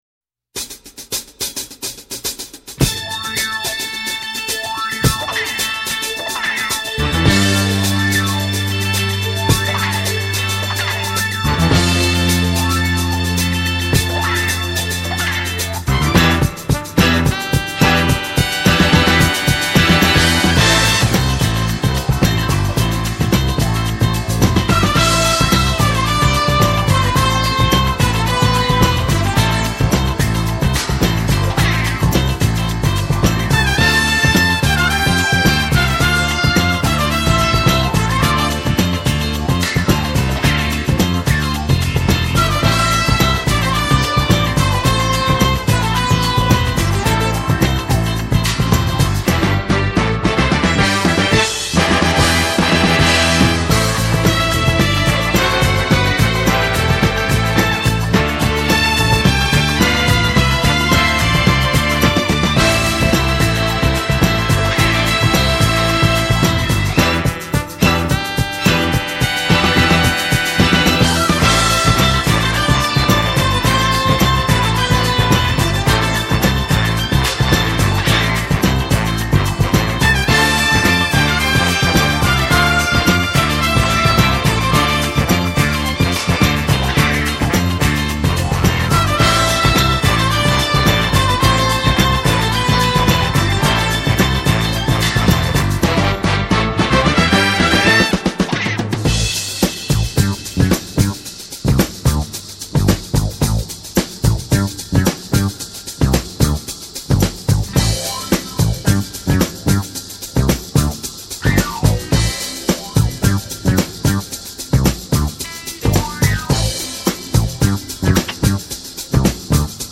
（BGM